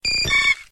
Cri de Papilusion dans Pokémon X et Y.